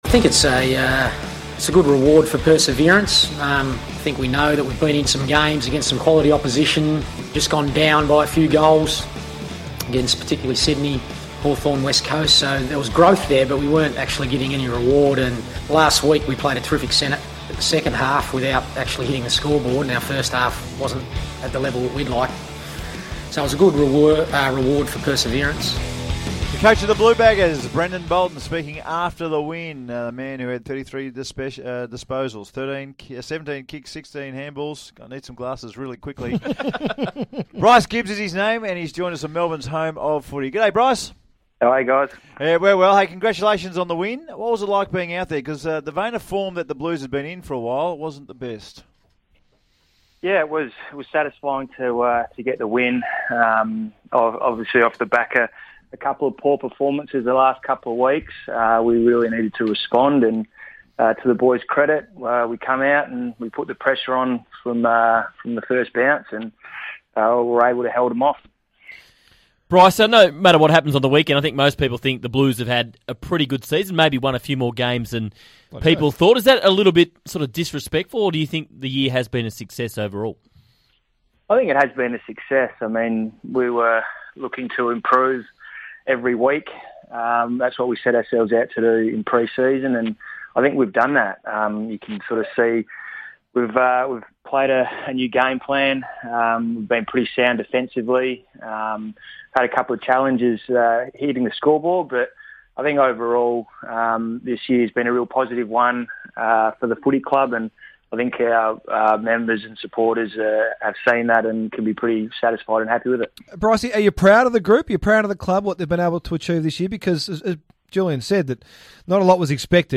Carlton midfielder Bryce Gibbs speaks to SEN's The Run Home after the Blues' 20-point win over Melbourne.